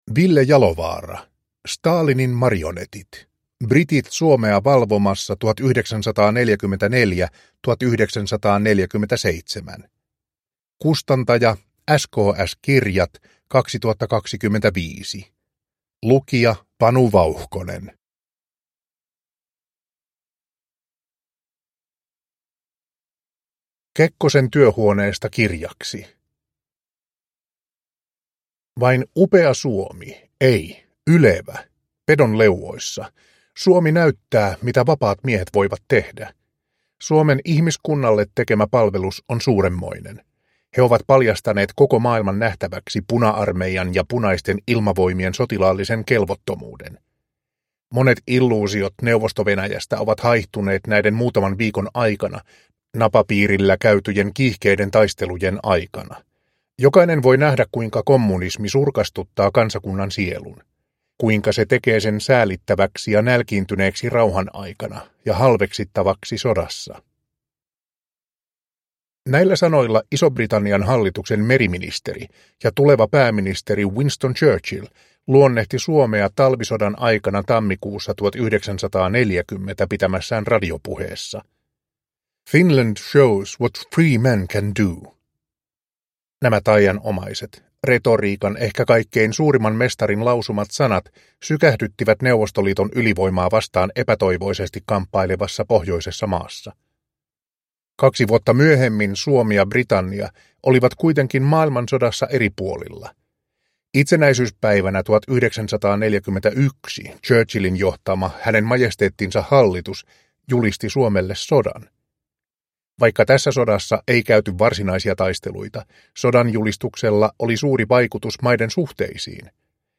Stalinin marionetit? – Ljudbok